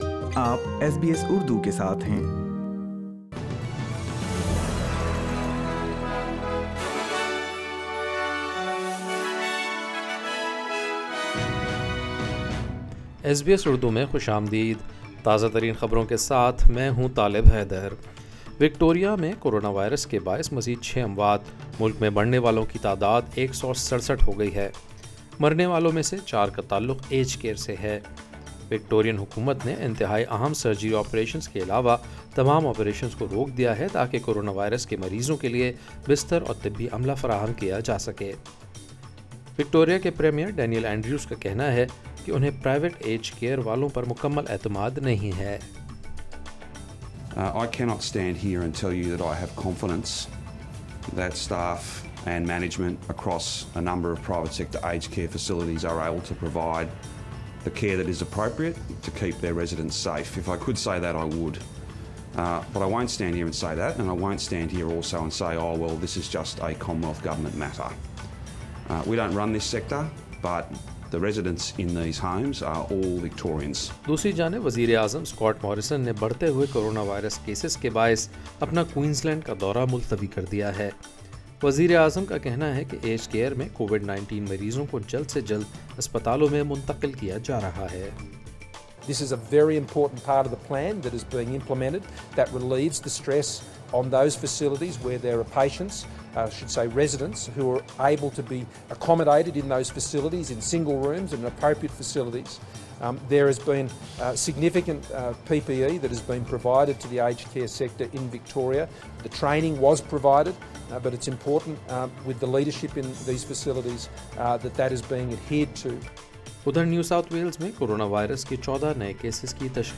ایس بی ایس اردو خبریں 28 جولائی 2020